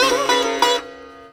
SITAR GRV 11.wav